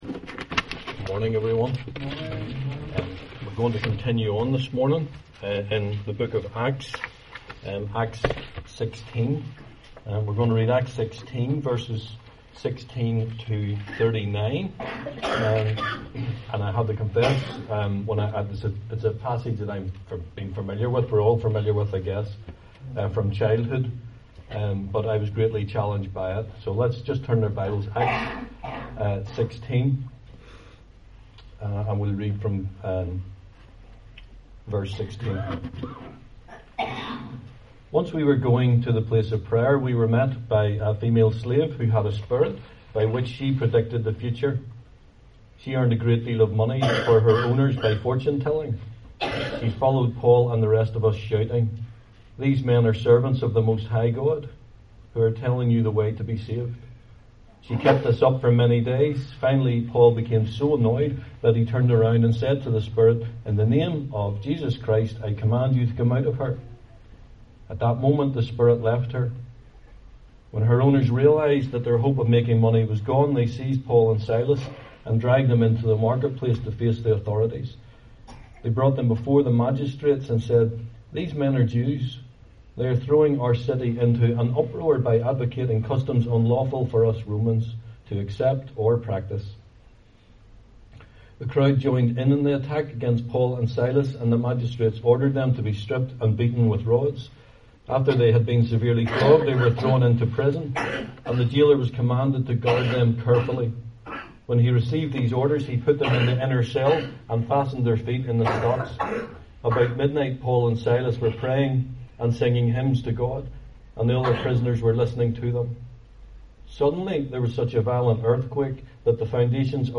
Service Type: 11am